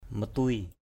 /mə-tuɪ/